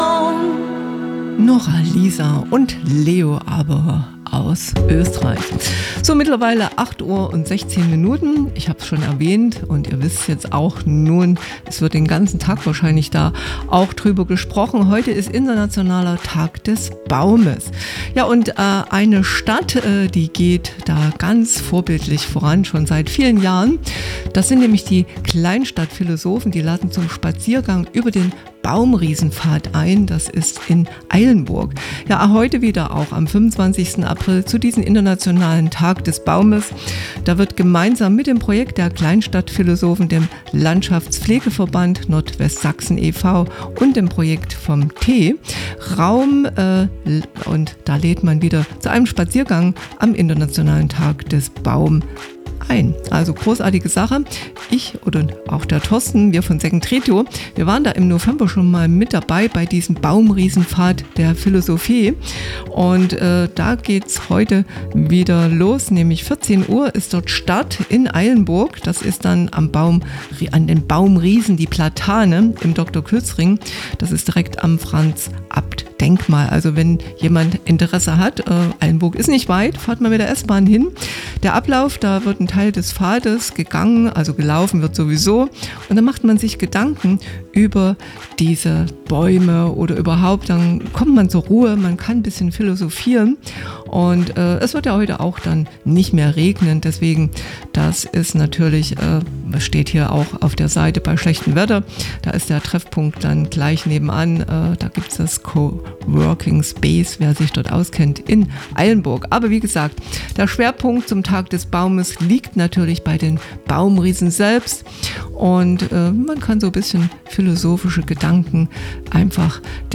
Ankündigung bei SecondRadio im Morgenwecker, 25.04.2025, 08.16 Uhr